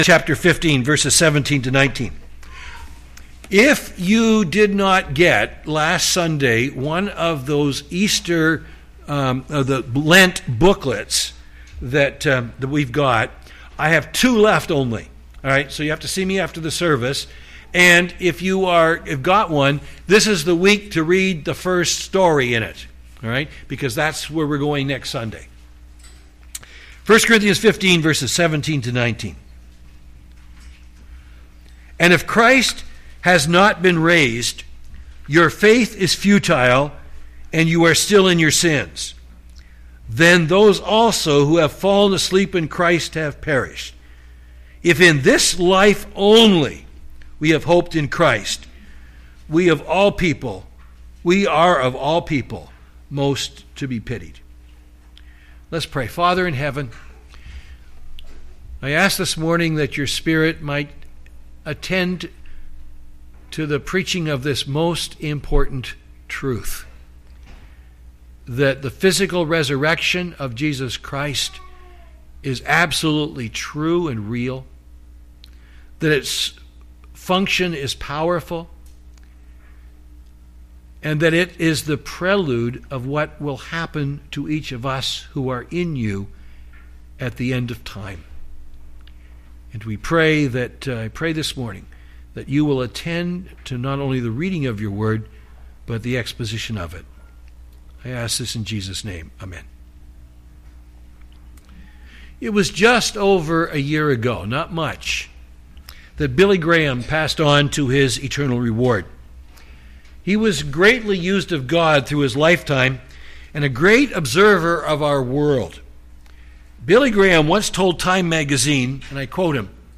Sermons | Bethel Bible Church